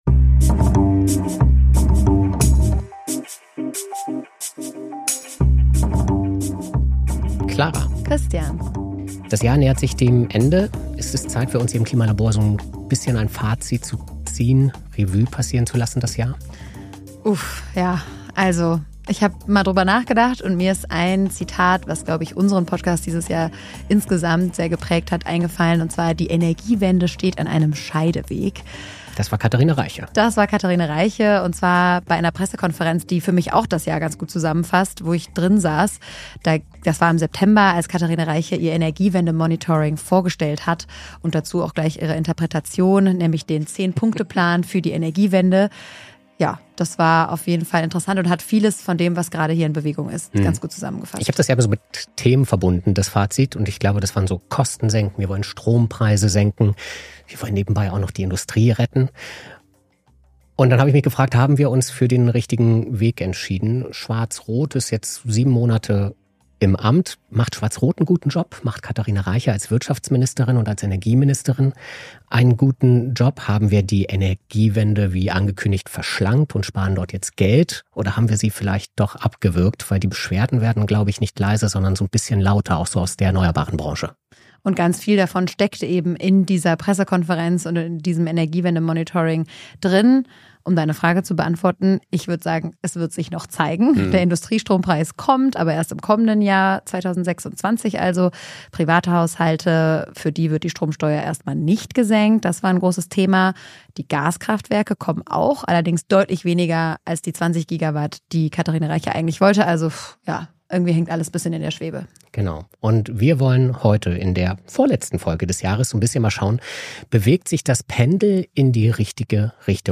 Dann bewertet das "Klima-Labor" bei Apple Podcasts oder Spotify Das Interview als Text?